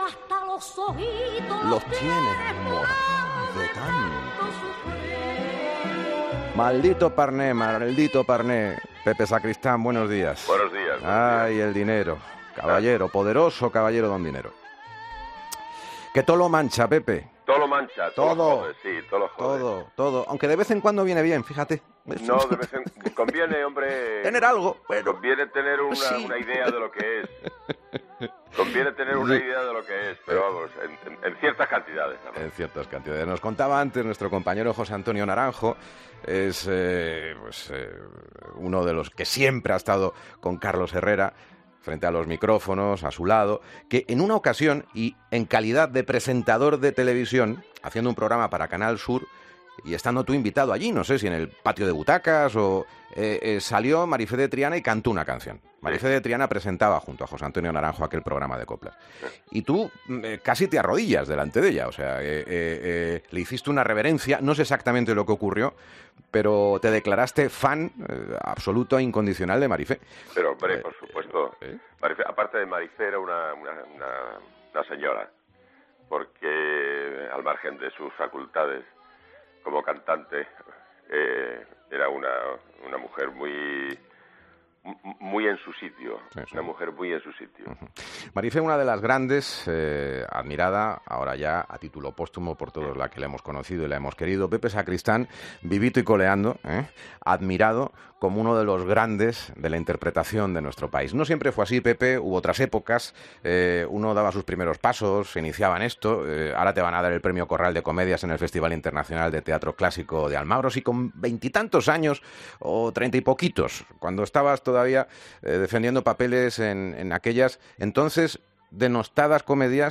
Escucha al actor José Sacristán